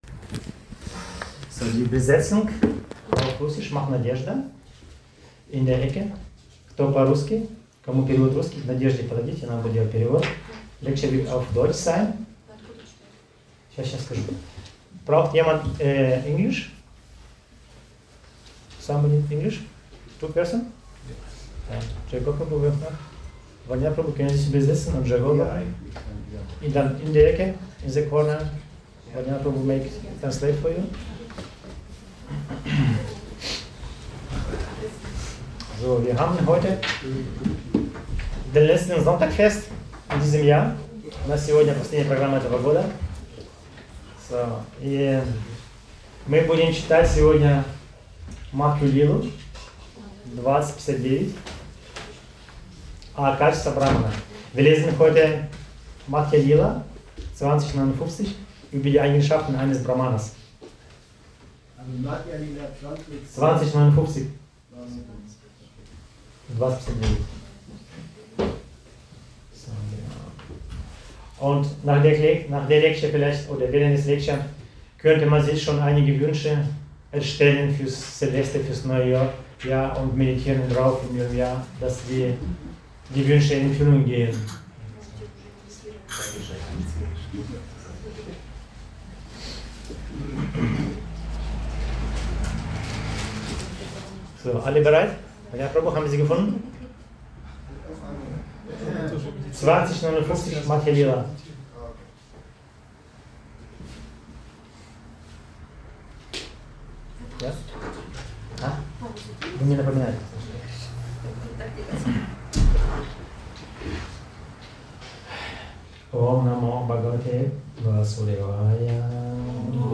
Eigenschaften eines Brahmanas – Vortrag